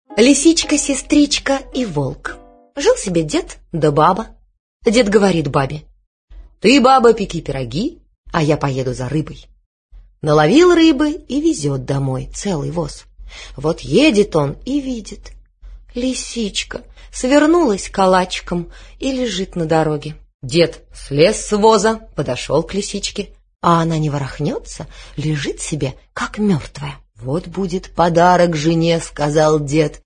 Аудиокнига Лисичка-сестричка и волк | Библиотека аудиокниг